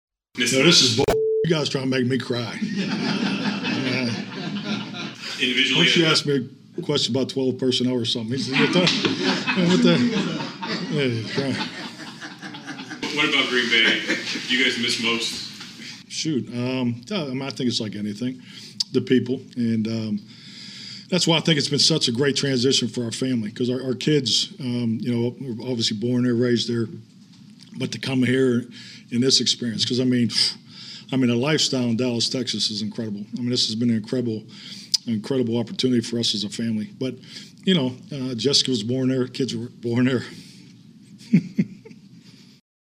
His usual press conference in Dallas and a conference call with Green Bay and Wisconsin reporters.
But when pressed a little more about what made his time in Green Bay special, McCarthy unabashedly became emotional.
mccarthy-emotion-return.mp3